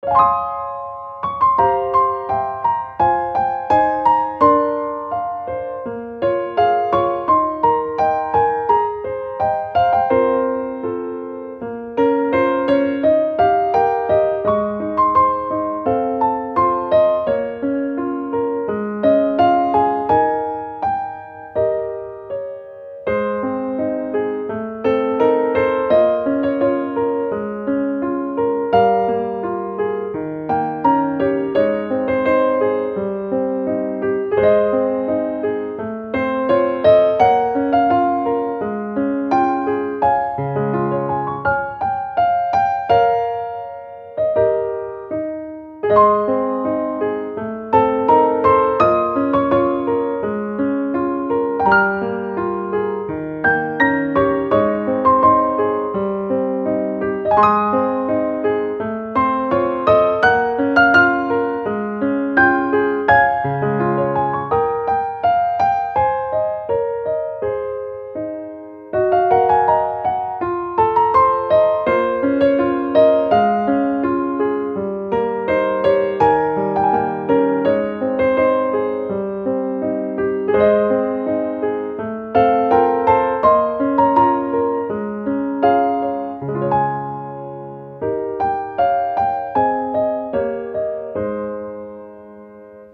• 暗めのしっとりしたピアノ曲のフリー音源を公開しています。
ogg(R) 楽譜 きらめき メロディアス バラード